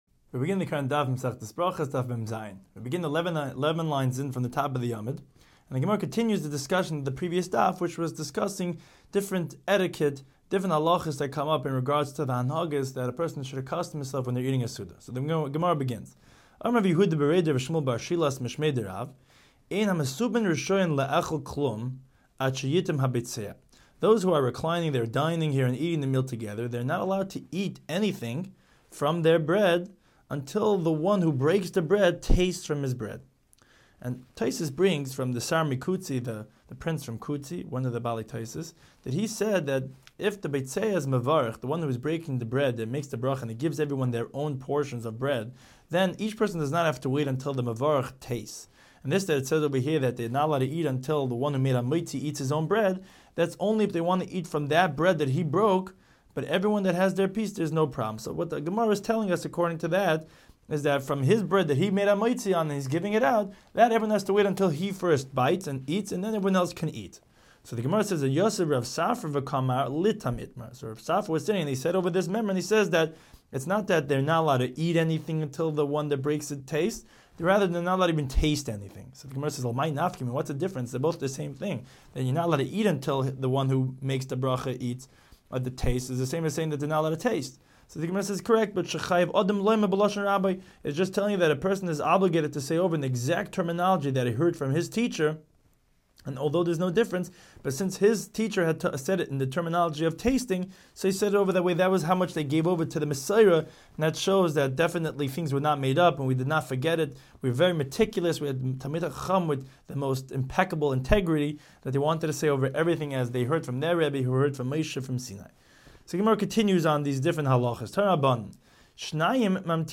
Daf Hachaim Shiur for Berachos 47